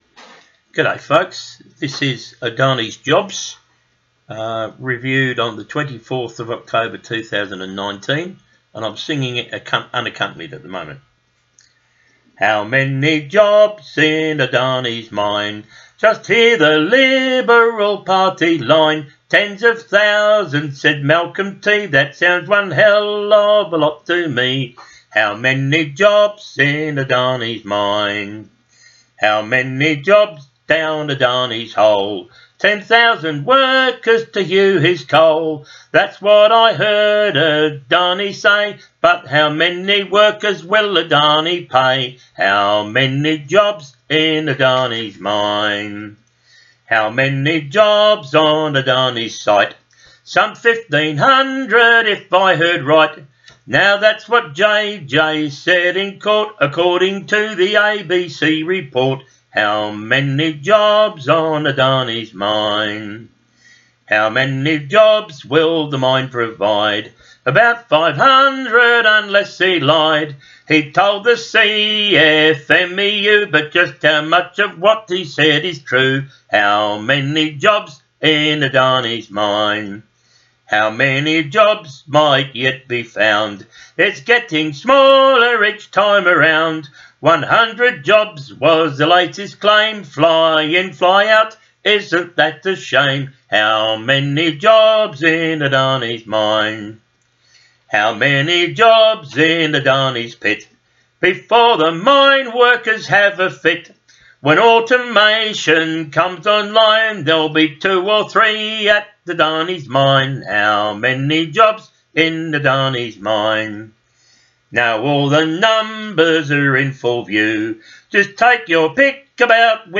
protest song